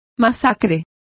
Complete with pronunciation of the translation of massacre.